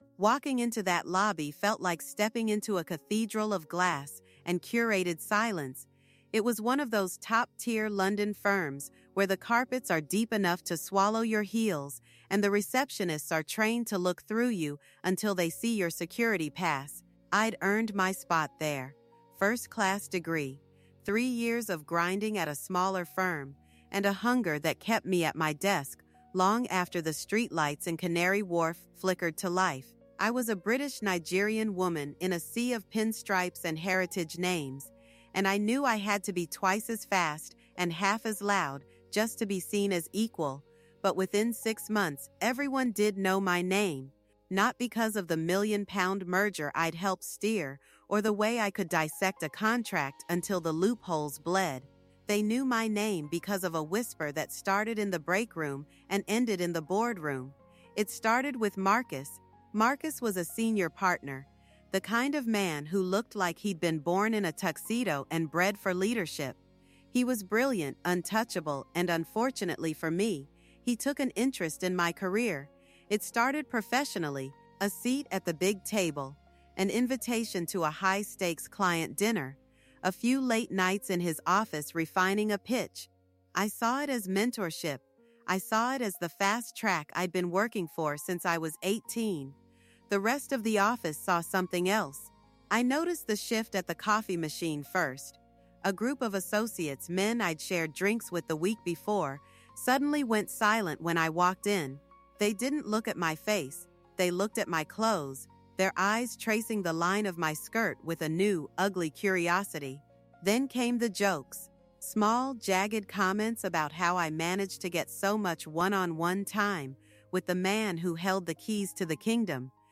Our narrator, a brilliant British-Nigerian woman who has climbed the corporate ladder through sheer talent and late nights in Canary Wharf, finds her reputation dismantled not by her performance, but by a devastating whisper campaign. When a senior partner’s mentorship is weaponized by jealous colleagues into a scandalous rumor, she discovers how quickly ambition can be reduced to a "workplace entanglement."